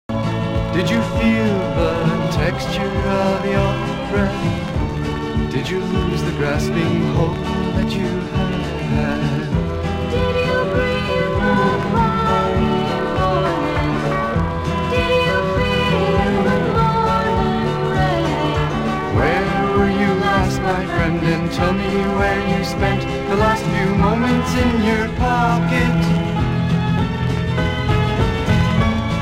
(税込￥1650)   US PSYCH